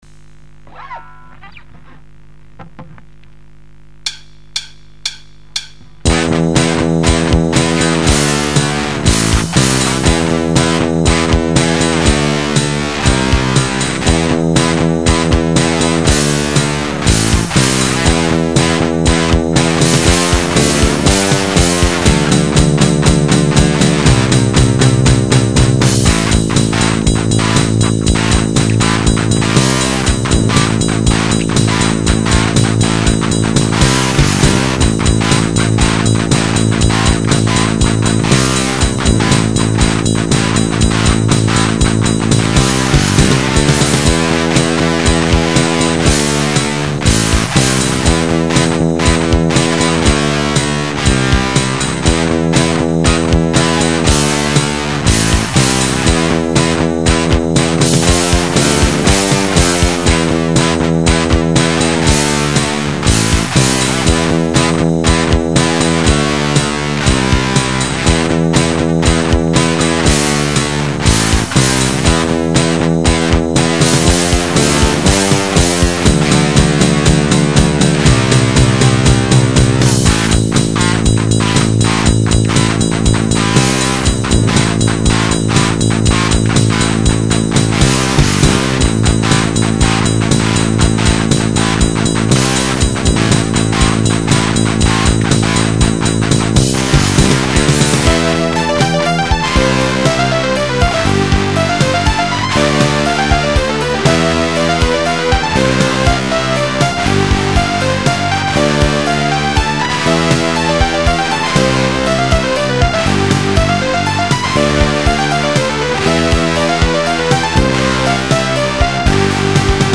Rythmé, mélodique et rageur.
Instruments : guitare, séquencer